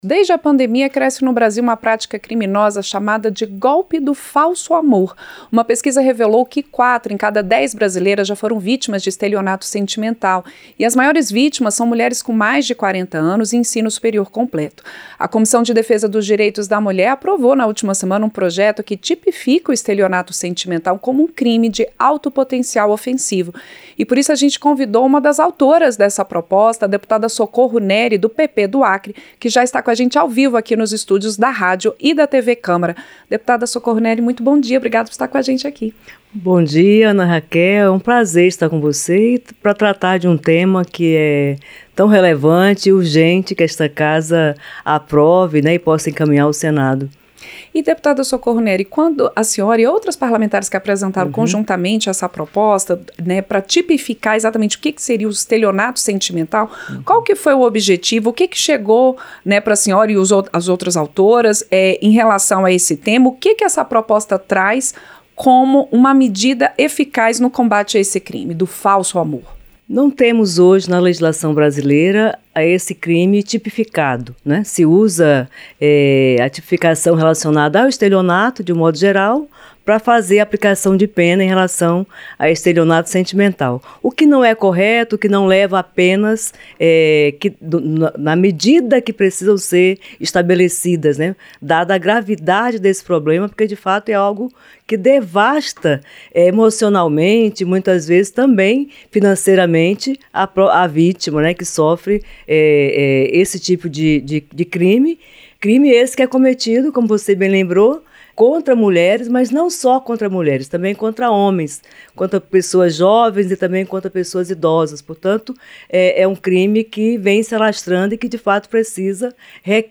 Entrevista - Dep. Socorro Neri (PP-AC)